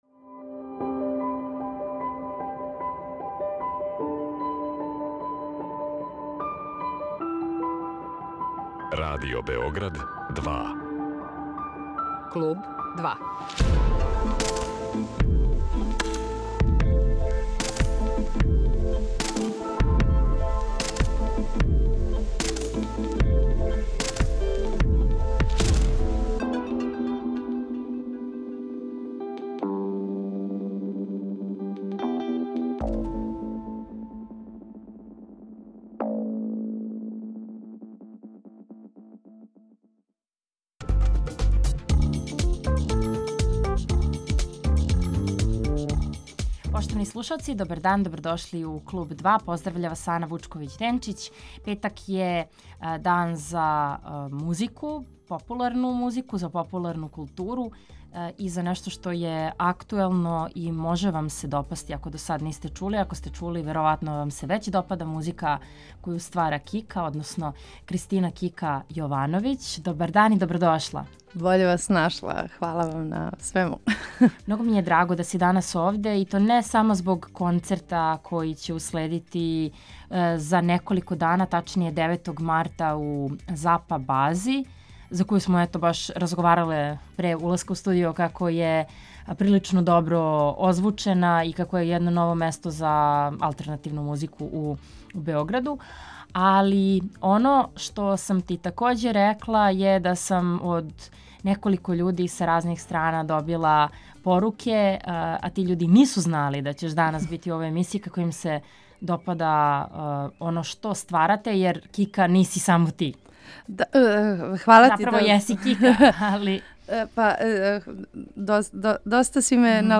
Аудио подкаст